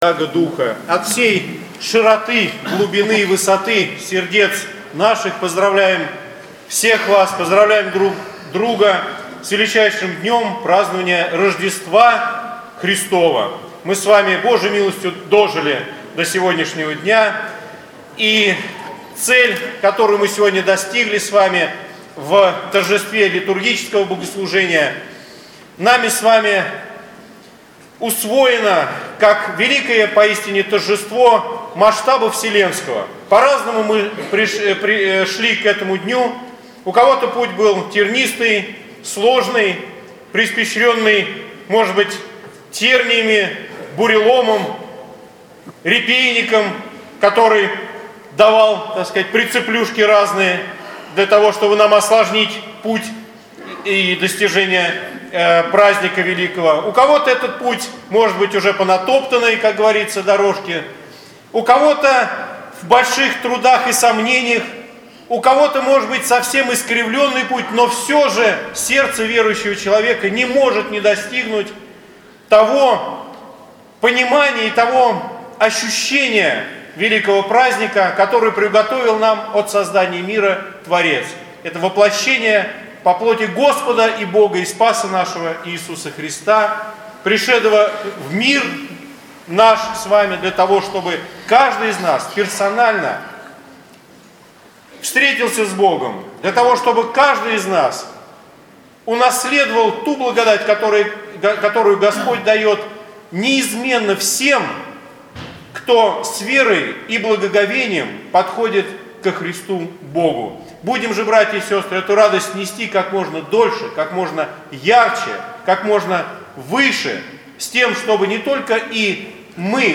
Ночная Божественная Литургия в нашем храме в праздник Рождества Христова